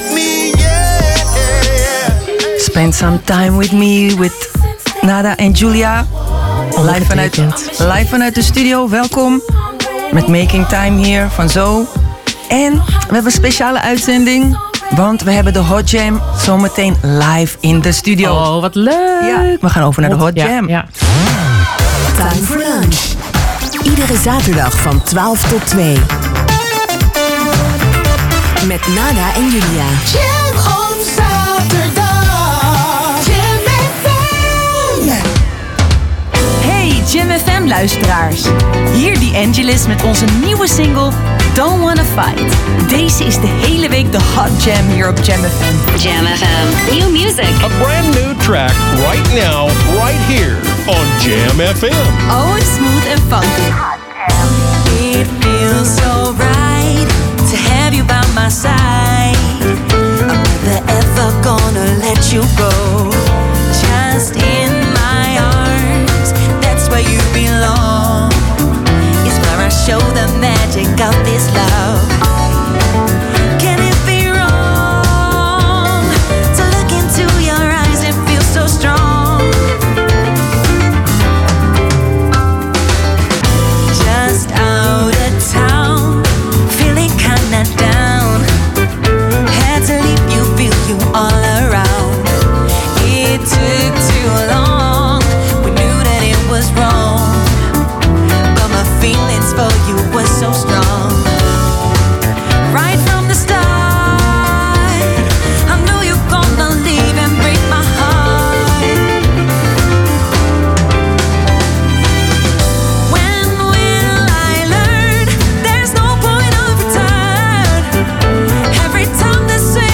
Beluister hier de hele uitzending terug: